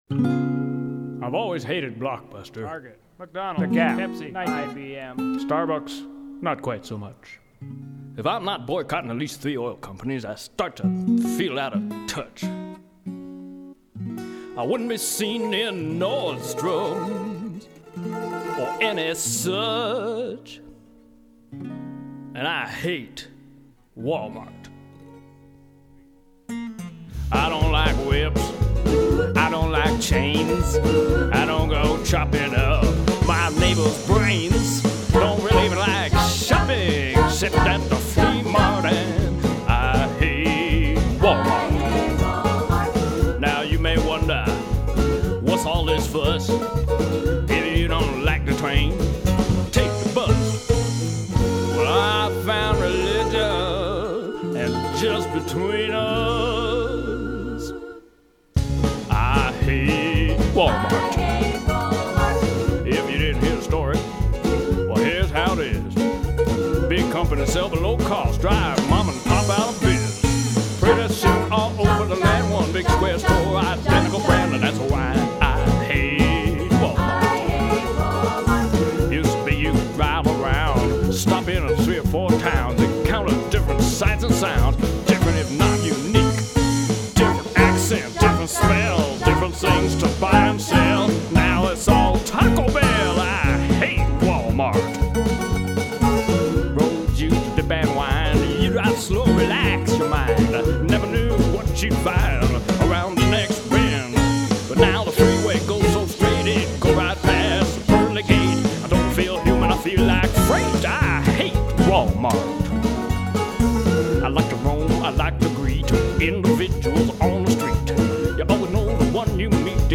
backup vocals
mandolin
drums